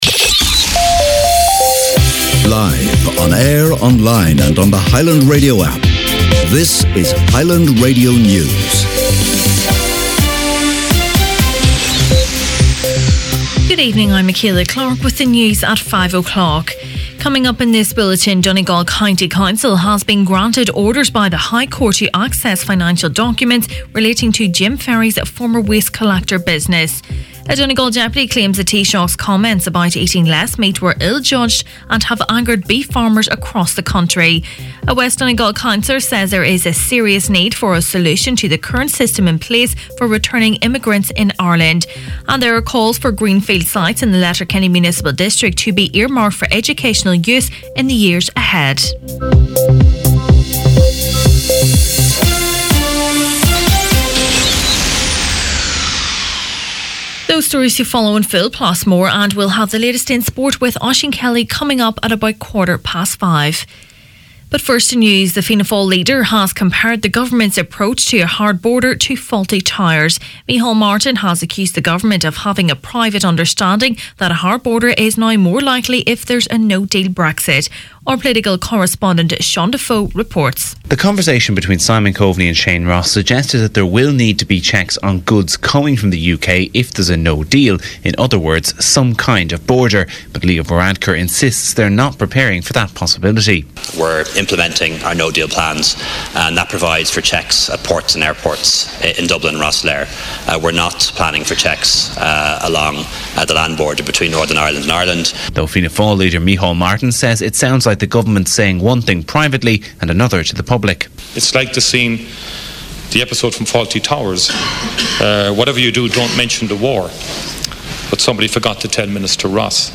Main Evening News, Sport and Obituaries Wednesday January 16th